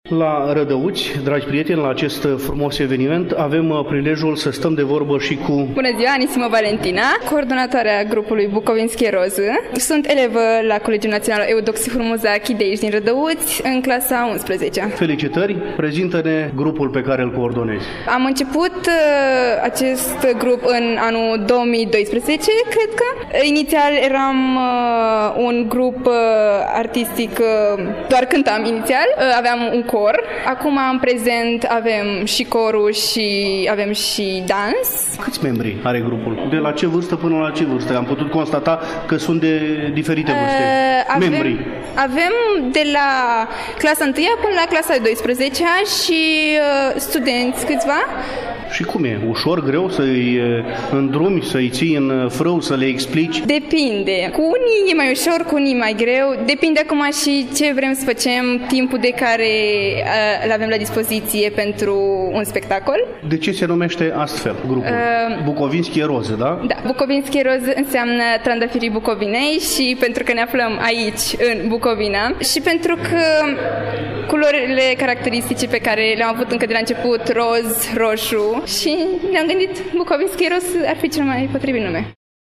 Reamintim, stimați ascultători, că în ediția de astăzi a emisiunii noastre relatăm de la Rădăuți, mai exact de la Ziua Comunității Rușilor Lipoveni din România și Ziua Limbii Materne, acțiuni desfășurate, duminică, 17 februarie, atât în sala de evenimente a Hotelului Geralds, cât și în incinta Casei de Cultură a Municipiului.